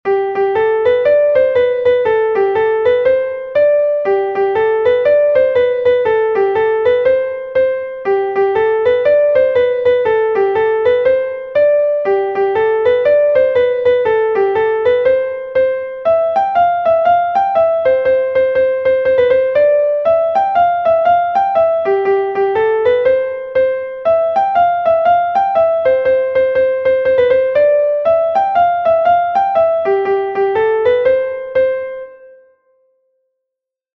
Gavotte de Bretagne